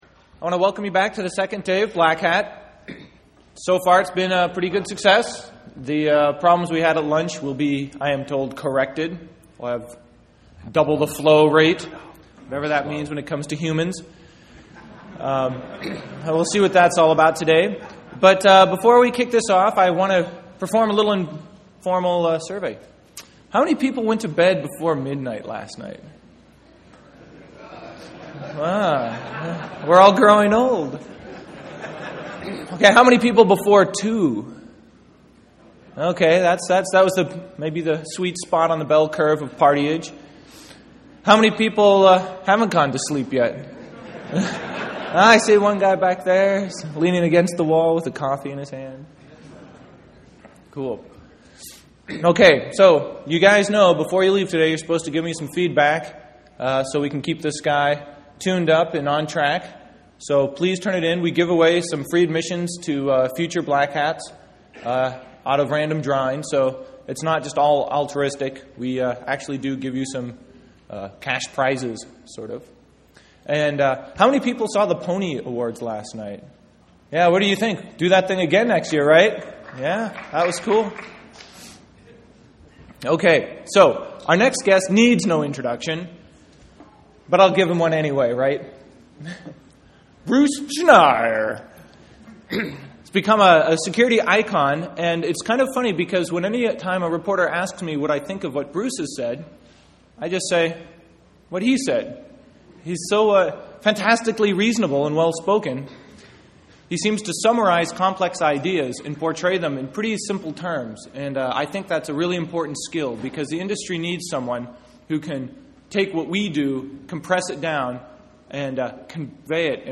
Bruce Schneier on Psychology of SecurityBlack Hat USA 2007
Fascinating presentation about how our many brain and mind biases affect the way we make security decisions.